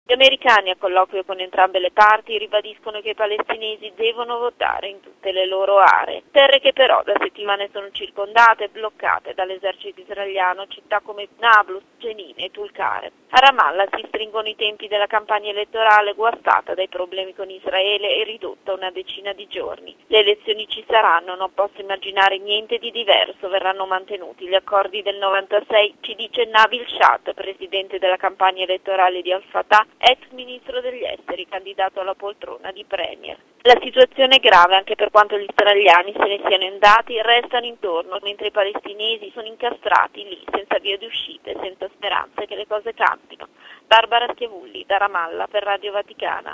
Intanto, i palestinesi continuano a preparare le elezioni legislative del 25 gennaio prossimo. Domani il governo israeliano dirà se si potrà votare a Gerusalemme est. Da Ramallah